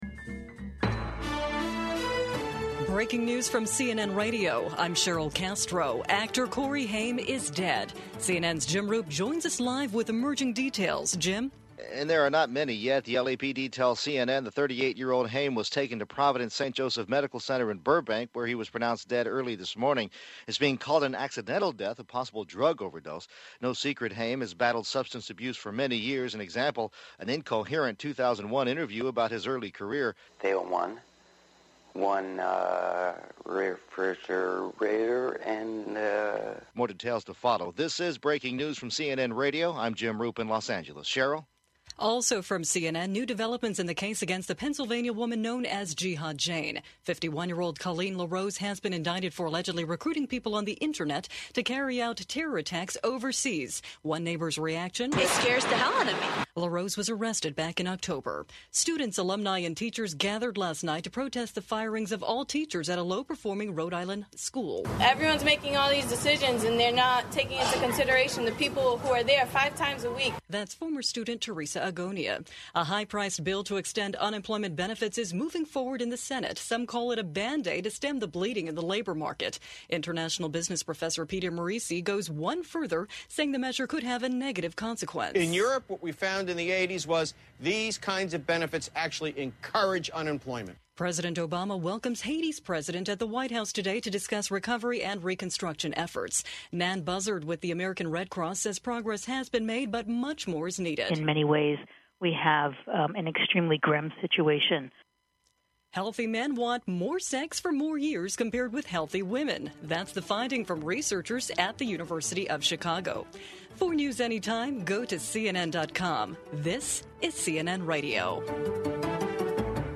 FYI, the format has changed a bit, so the first 3:36 of the show is news updates.